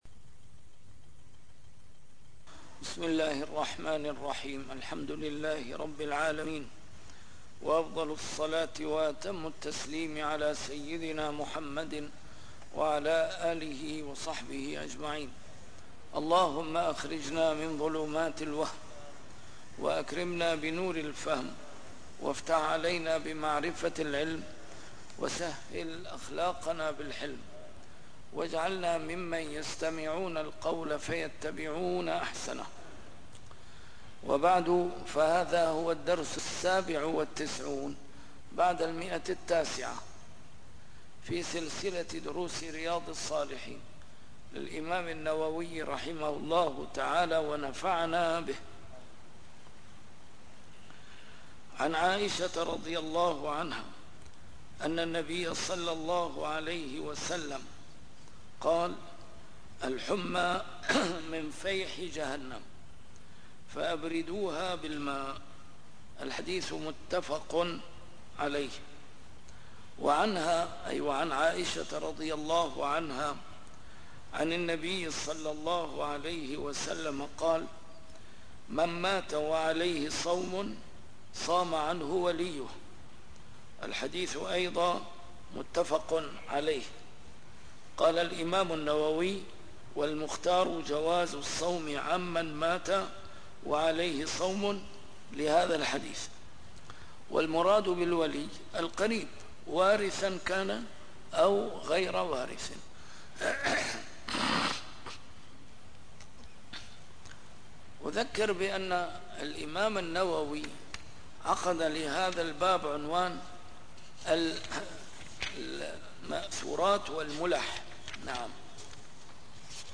A MARTYR SCHOLAR: IMAM MUHAMMAD SAEED RAMADAN AL-BOUTI - الدروس العلمية - شرح كتاب رياض الصالحين - 997- شرح رياض الصالحين: بابُ المنثورات والمُلَح